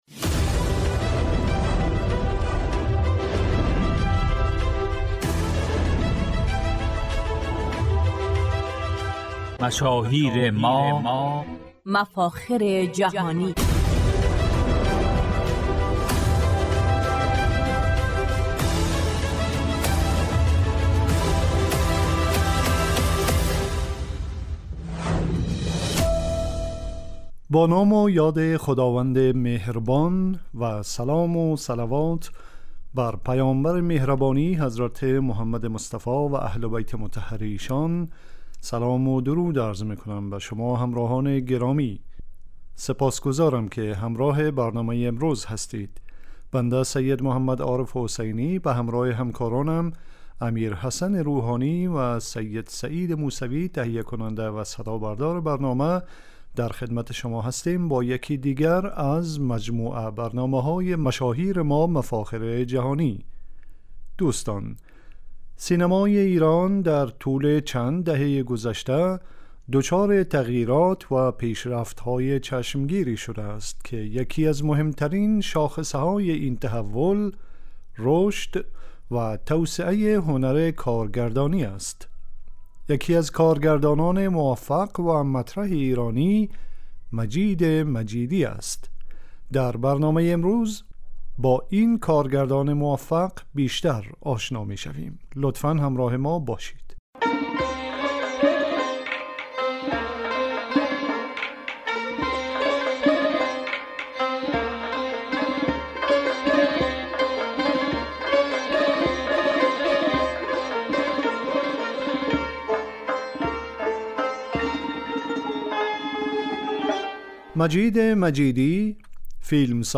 در این برنامه "مجید مجیدی" یکی از مفاخر ایران زمین معرفی می شود. روزهای سه شنبه ساعت 15از رادیو دری شنونده این برنامه باشید...